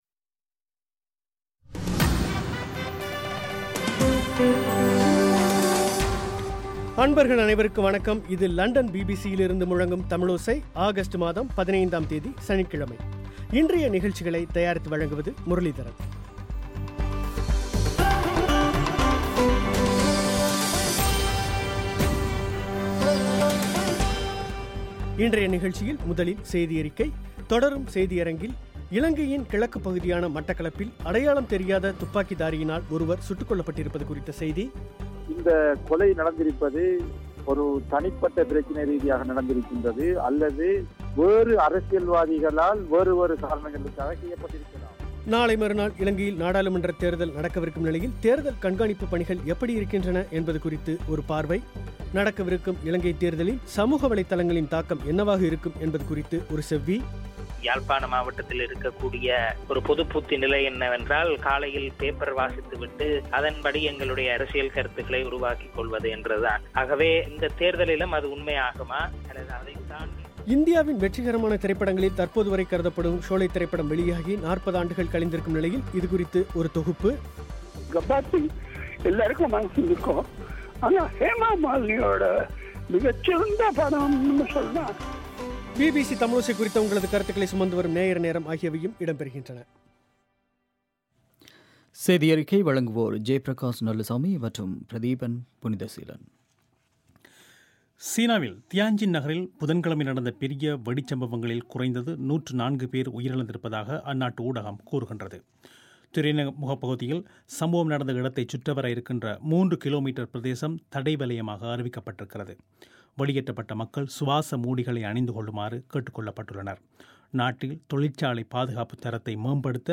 தேர்தலில் சமூக வலைத்தளங்களின் தாக்கம் குறித்து ஒரு செவ்வி